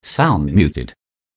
sound.muted.wav